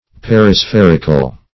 Search Result for " perispherical" : The Collaborative International Dictionary of English v.0.48: Perispheric \Per`i*spher"ic\, Perispherical \Per`i*spher"ic*al\, a. Exactly spherical; globular.
perispherical.mp3